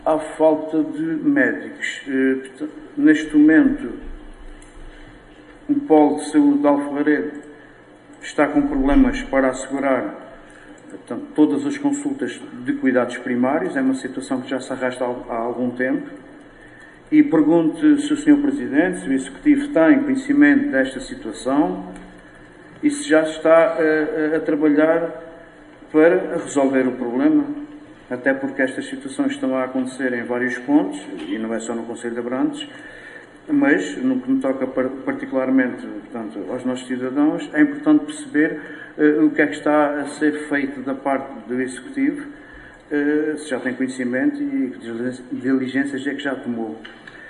A falta de médicos voltou a ser assunto na reunião de executivo de Abrantes.
ÁUDIO | ARMINDO SILVEIRA, VEREADOR DO BLOCO DE ESQUERDA: